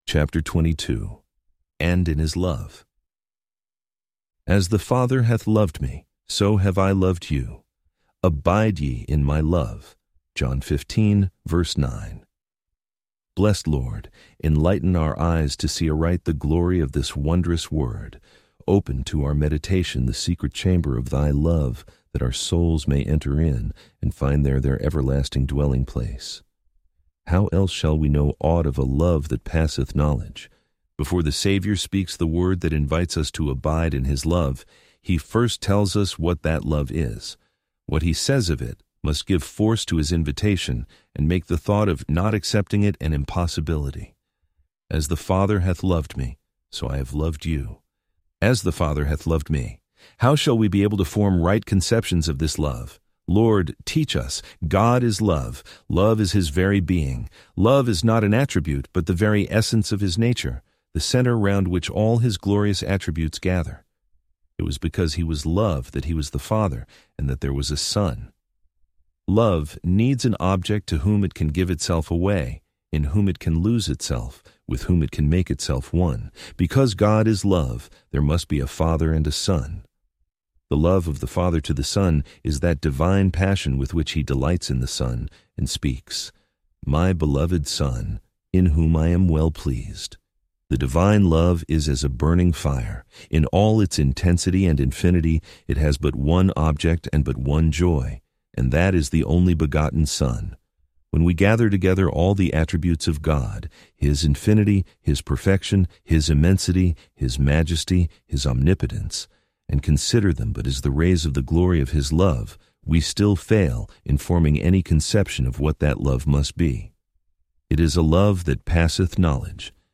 Complete Audiobook Play Download Individual Sections Section 1 Play Download Section 2 Play Download Listening Tips Download the MP3 files and play them using the default audio player on your phone or computer.